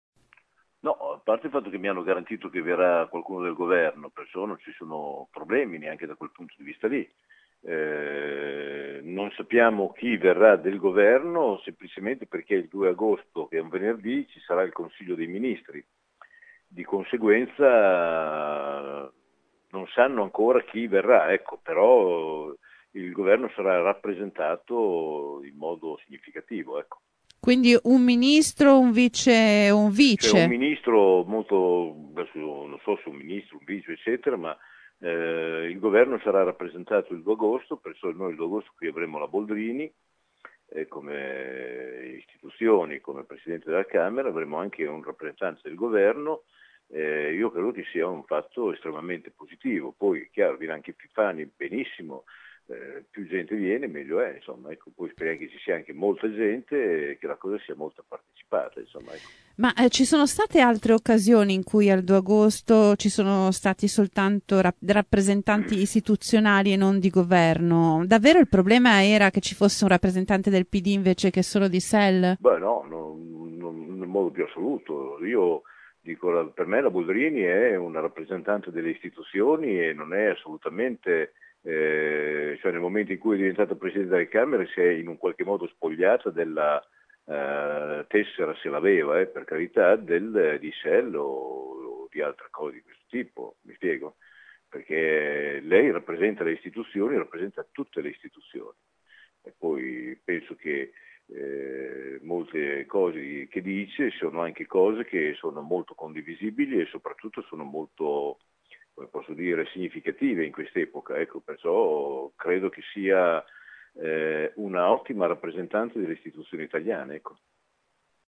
Bolognesi ai nostri microfoni si dice sicuro che un rappresentante del governo ci sarà: